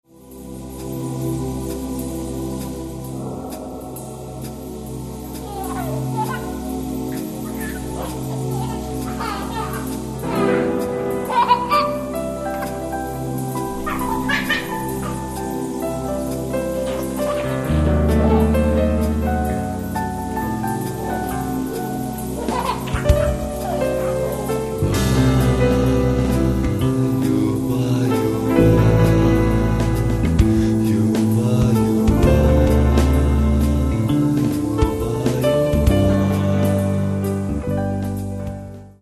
Каталог -> Классическая -> Нео, модерн, авангард
Внутри нее – просторно и легко.